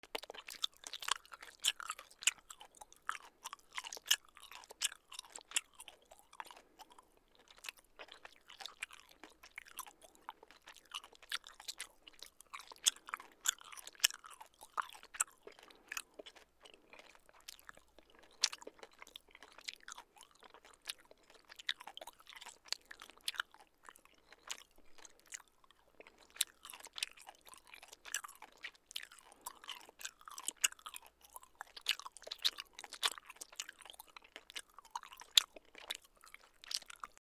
そしゃく2